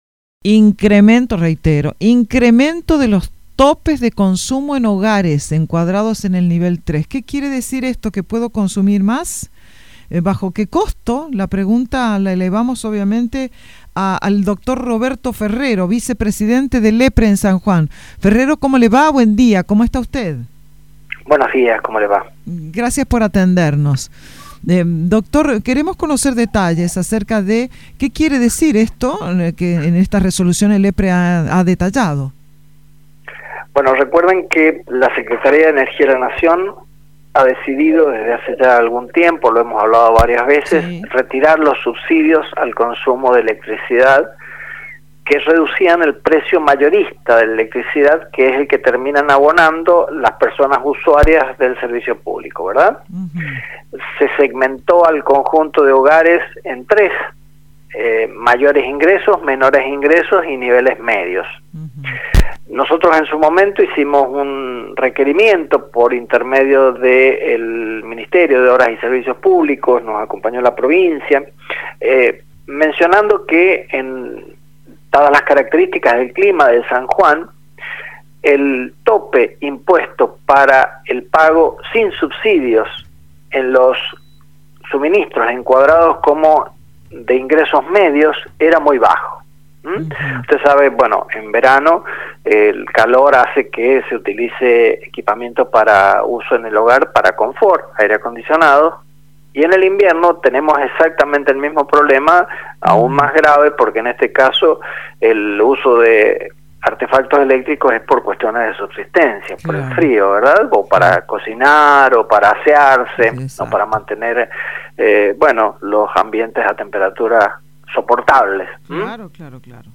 La media impacta en casi 40.000 hogares sanjuaninos, de acuerdo a lo que comunicó Roberto Ferrero vicepresidente del EPRE en San Juan, en comunicación con Radio Sarmiento.